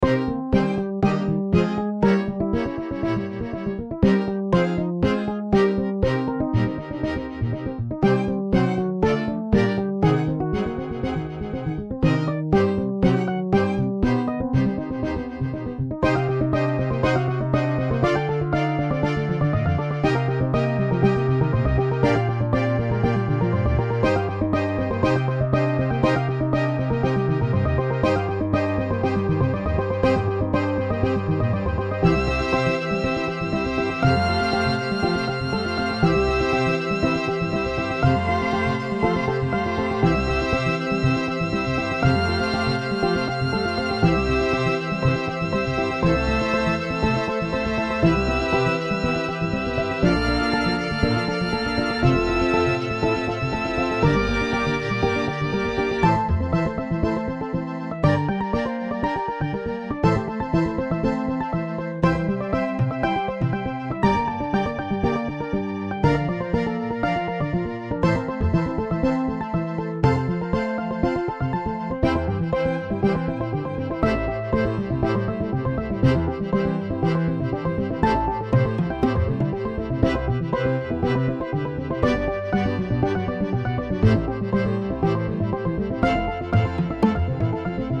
アコースティックベース、コントラバス、チェロ